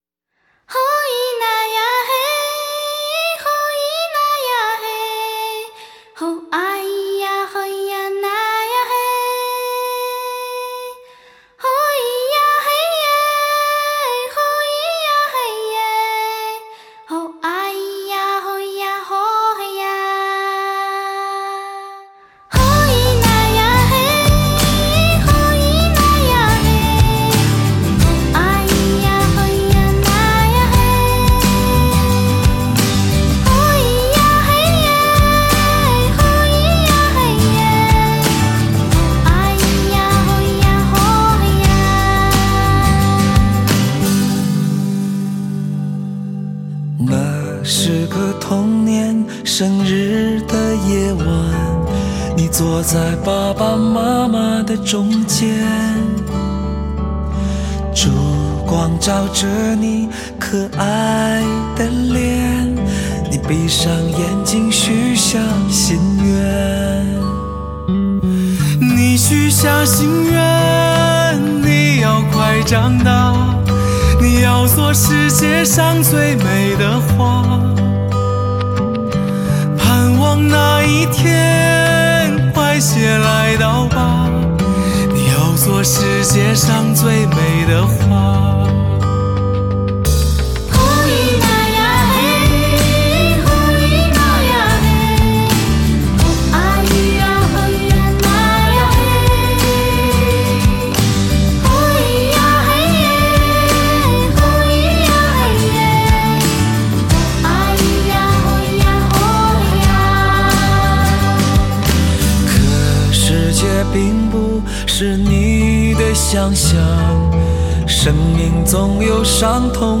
动听绝伦的人声响宴，无法抗拒的奢华诱惑。